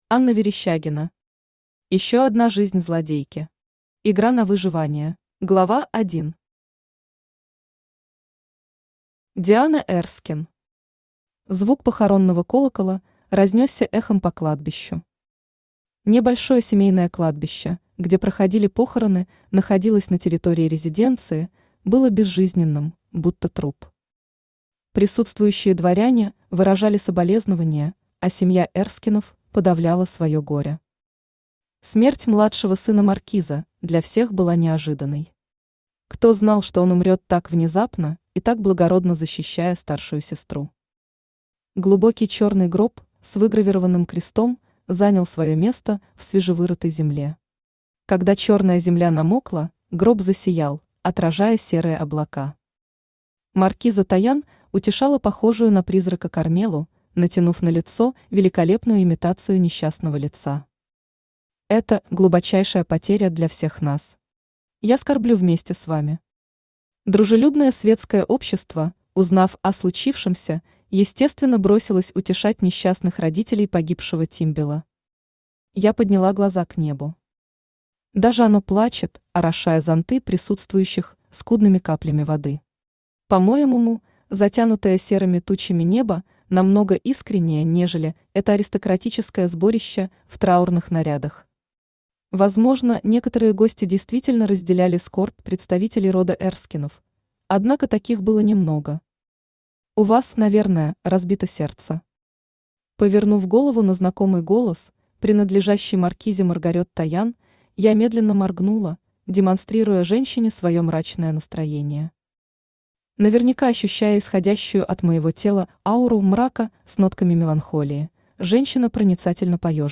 Игра на выживание Автор Анна Верещагина Читает аудиокнигу Авточтец ЛитРес.